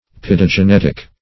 Search Result for " paedogenetic" : The Collaborative International Dictionary of English v.0.48: paedogenetic \p[ae]`do*ge*net"ic\ (p[=e]`d[-o]*j[-e]*n[e^]t"[i^]k), a. (Zool.) Producing young while in the immature or larval state; -- said of certain insects, etc. [1913 Webster]